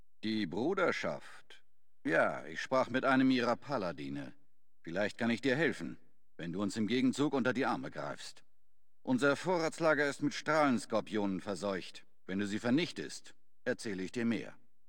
Kategorie:Fallout: Brotherhood of Steel: Audiodialoge Du kannst diese Datei nicht überschreiben.
FOBOS-Dialog-Richard-005.ogg